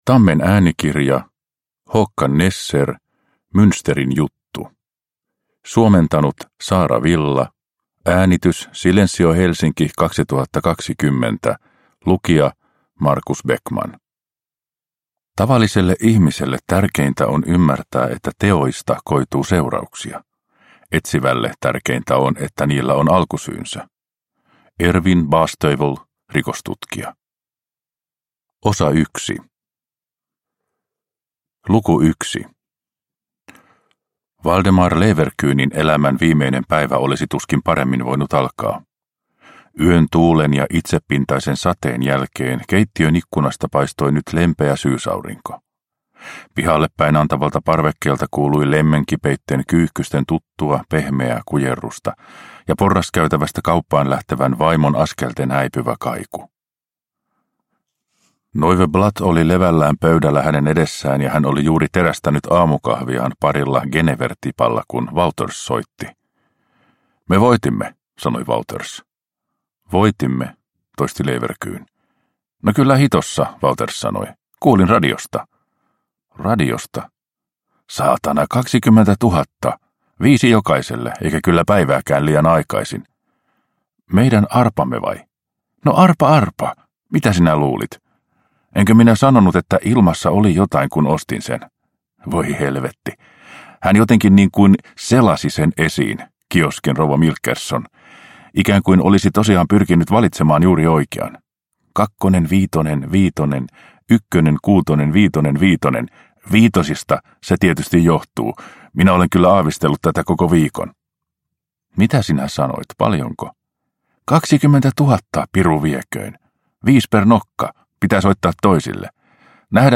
Münsterin juttu – Ljudbok – Laddas ner